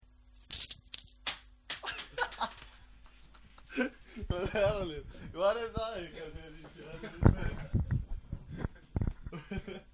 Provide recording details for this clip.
08/15/99 I'm currently using nearly all my spare time, working on Sail Ho!. Sometimes fun things happen when you are working on making sound effects.